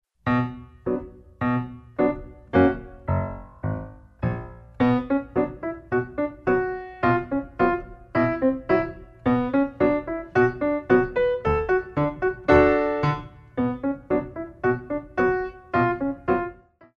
Piano selections include:
Frappé